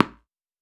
Wall Hit Powerful.wav